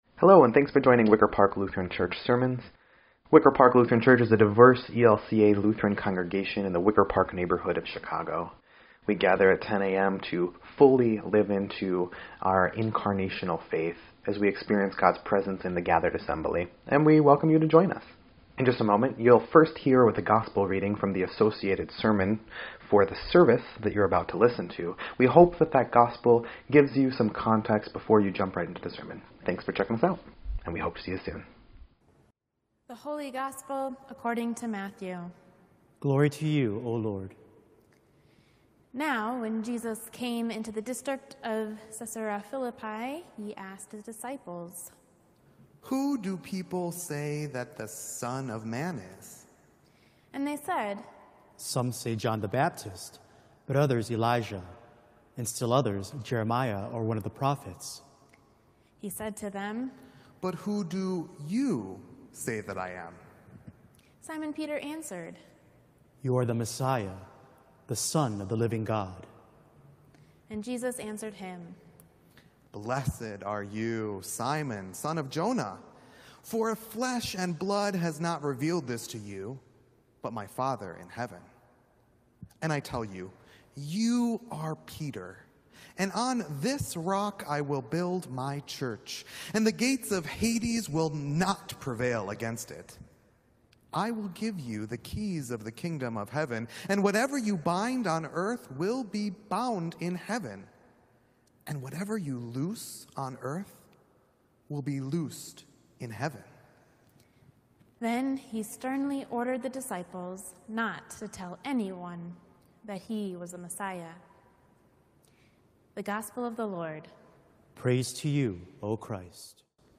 8.23.20_Sermon_EDIT.mp3